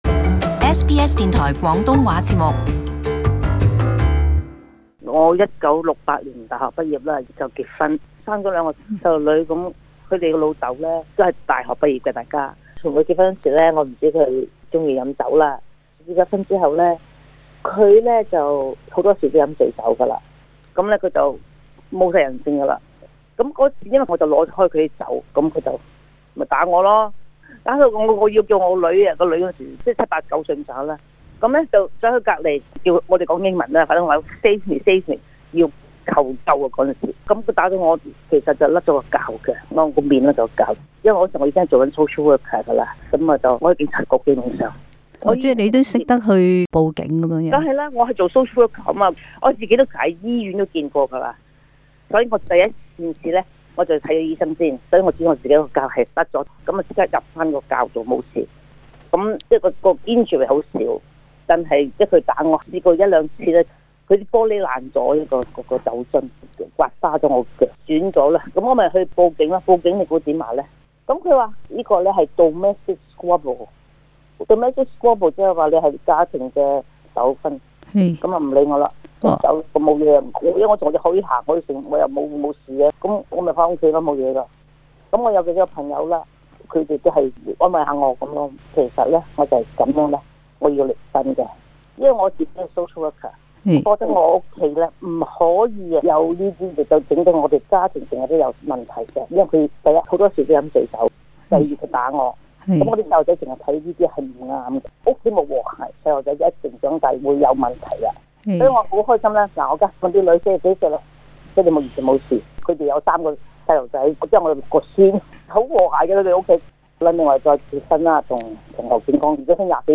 【社區專訪】前新州上議院議員何沈慧霞曾是家庭暴力受害者